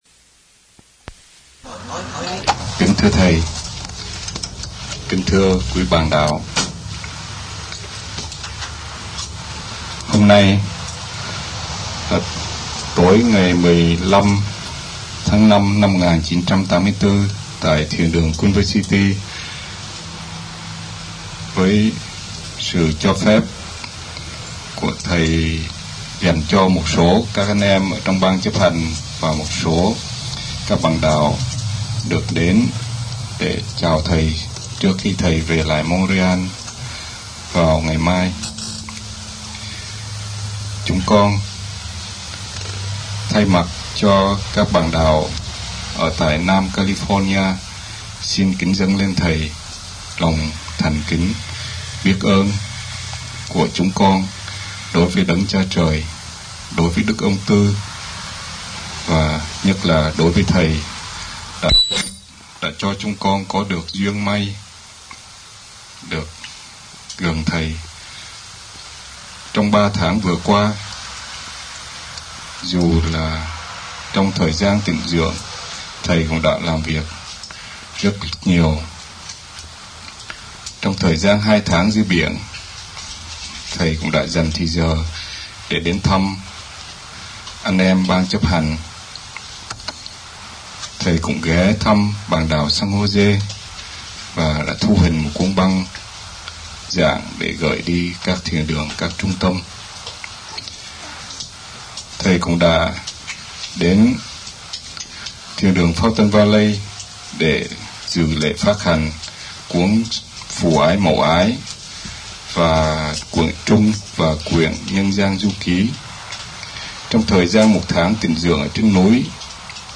United States Trong dịp : Sinh hoạt thiền đường >> wide display >> Downloads